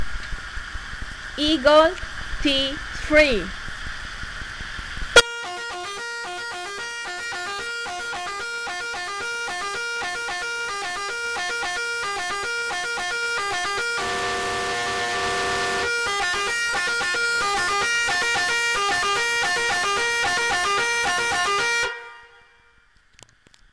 Fisa luchthoorn Eagle T3 24V | 146173
Fisa luchthoorn Eagle T3 24V116dB30WHigh Tone 490HzMiddle Tone 402HzLow Tone 360Hz